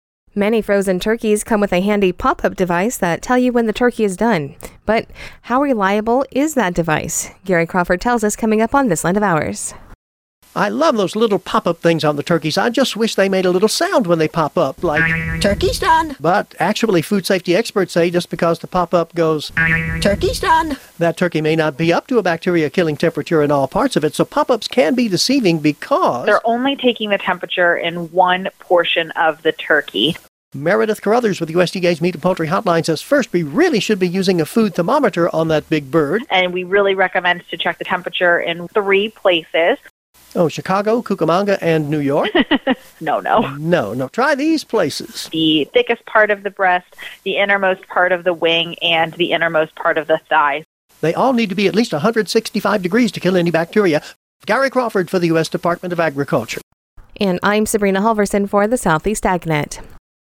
with USDA's Food Safety and Inspection Service has today's This Land Of Ours report.